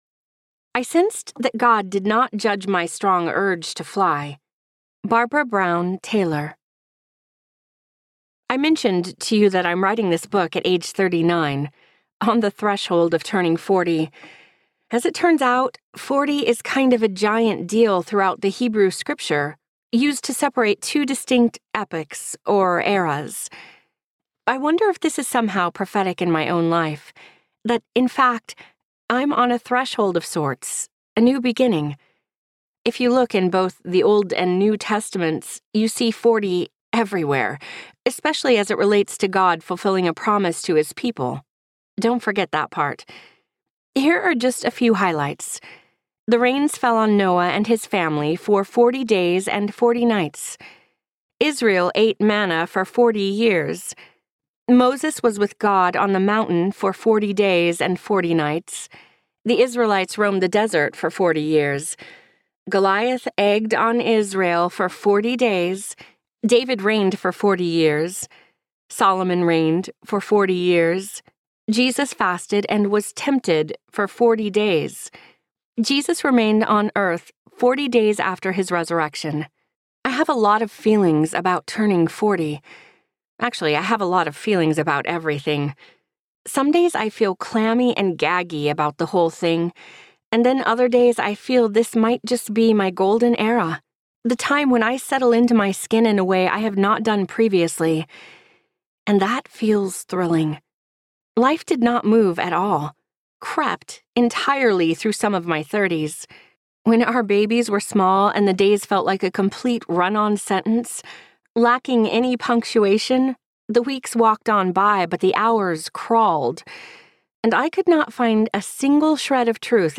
Brazen Audiobook
Narrator
6.12 Hrs. – Unabridged